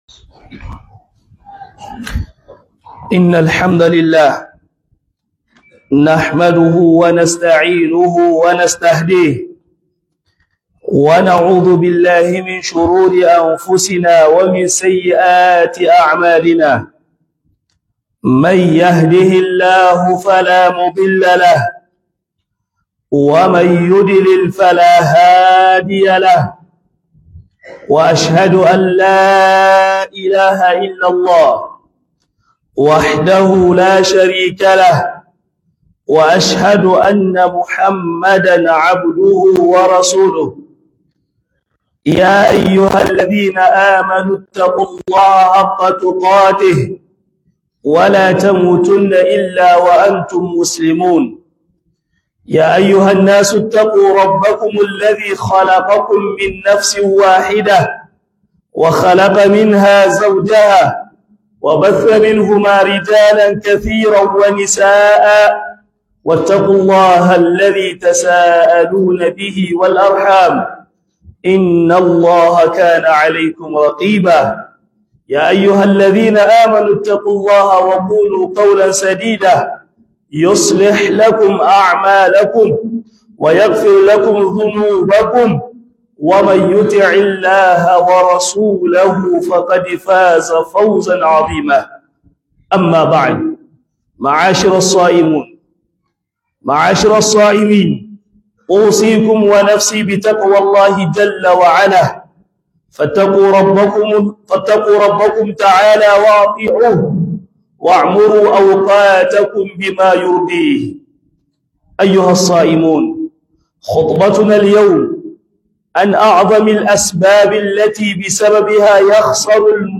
- HUDUBA